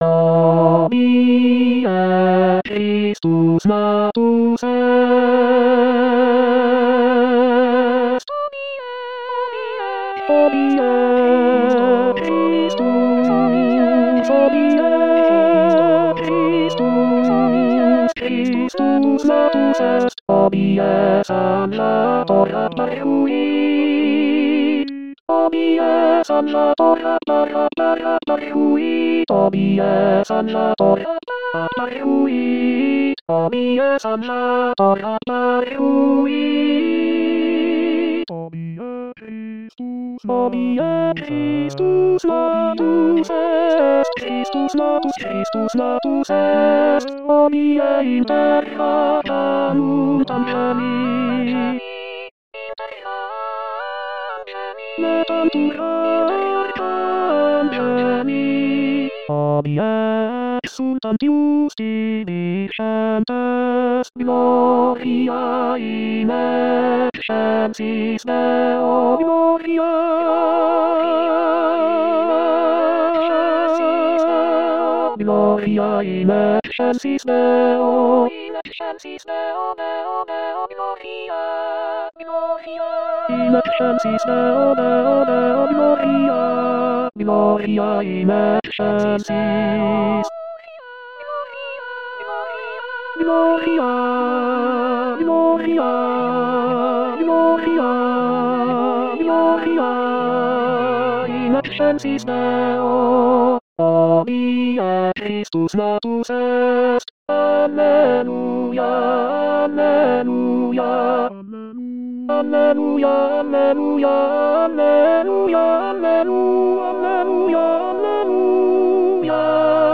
Tenor Tenor 1